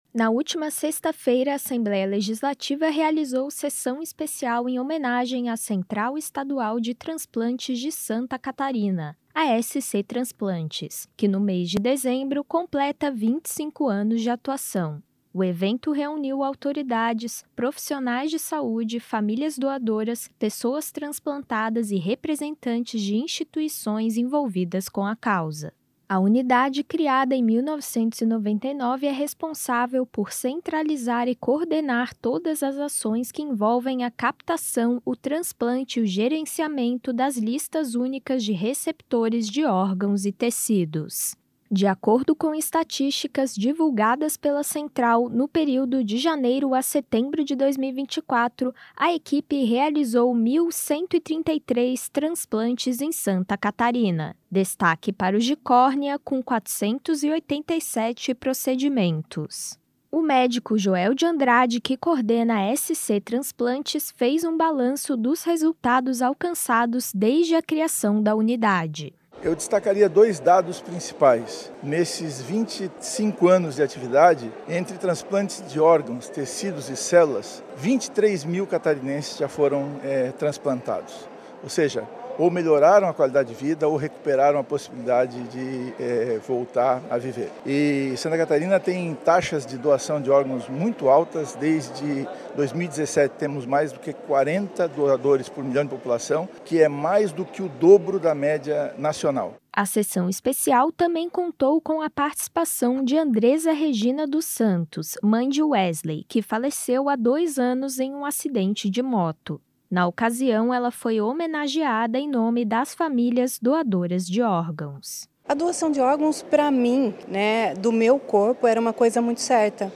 Entrevistas com:
- deputado Soratto (PL), proponente da homenagem.